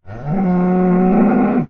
cow4.ogg